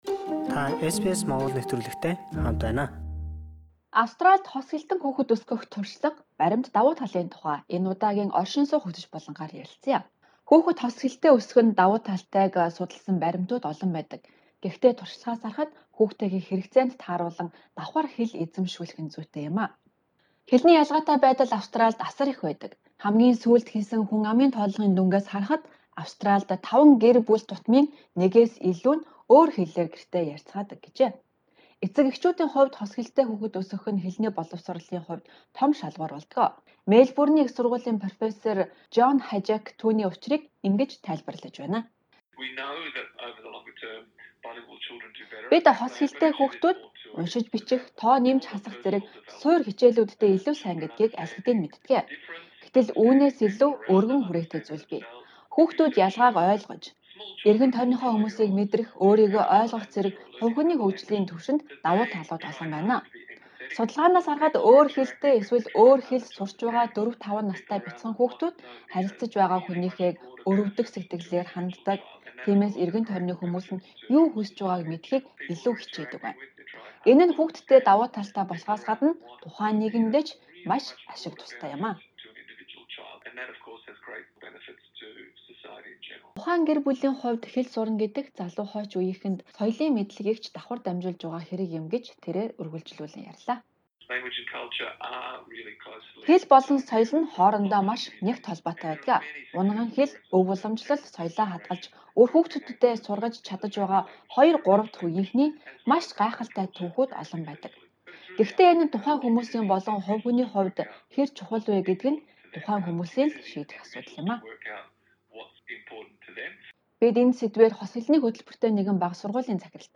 Энэ тухайд бид Монгол болон бусад орны хэл шинжээч эрдэмтэд, багш нарын яриа, Австралийн анхны хос хэлний боловсролын хөтөлбөрөөр сурсан хүний туршлагыг хүргэж байна.